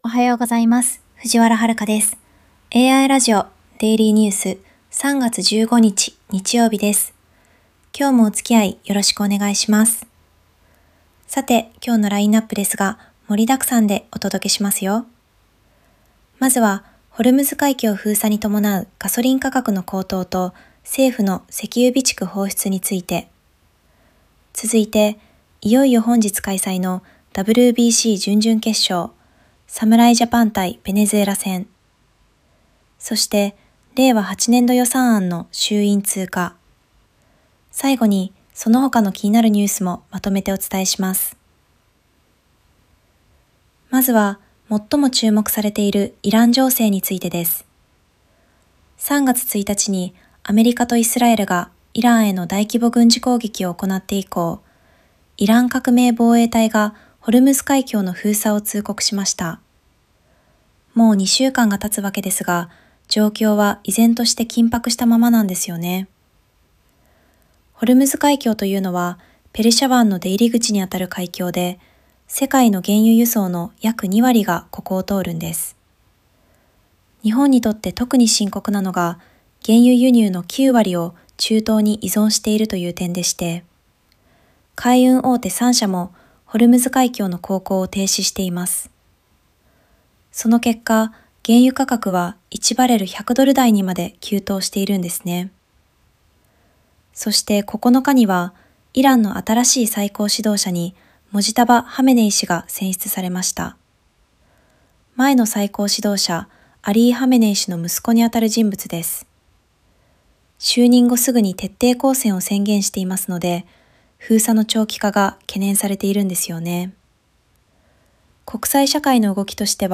ニュースキャスター